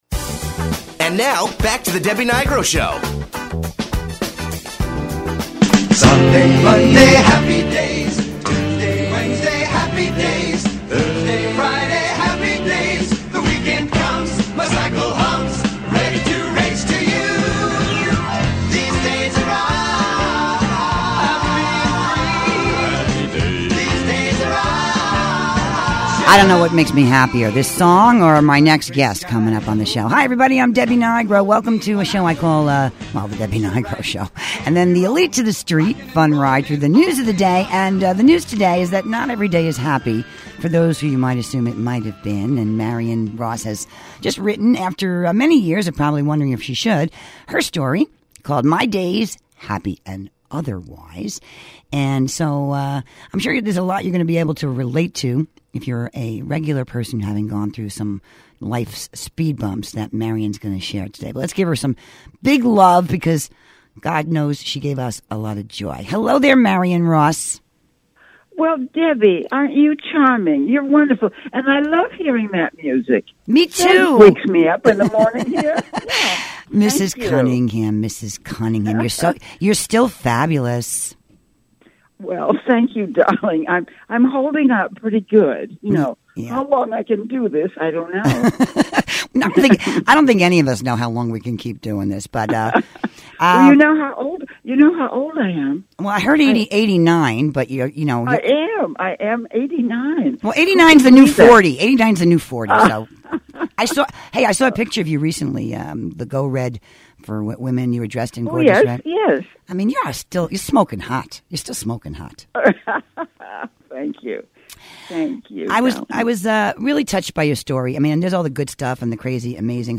interview Marion Ross on her Internet and radio show.